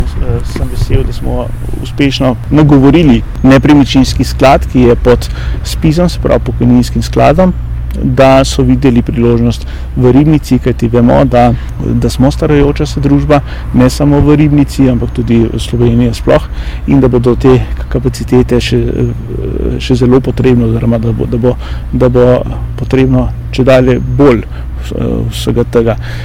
Ribniški župan Samo Pogorelc o napovedani gradnji stanovanj